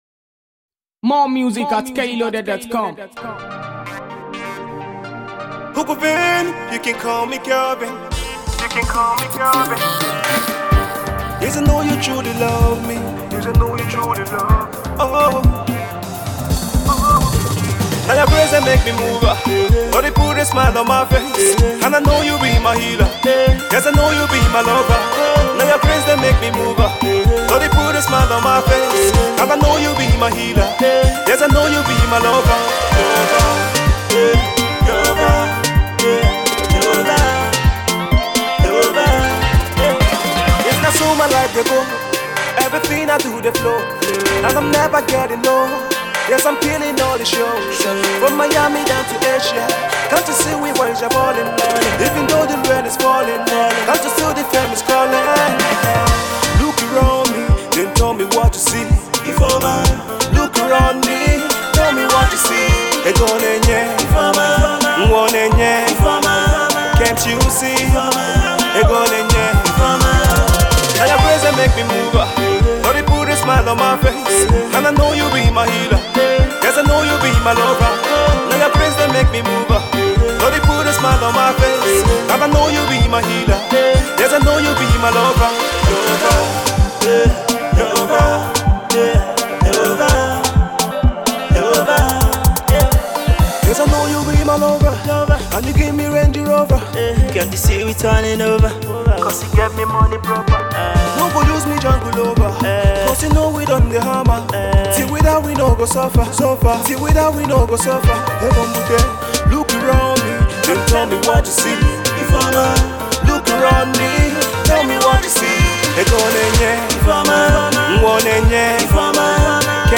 dance track